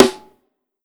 Living SNare.wav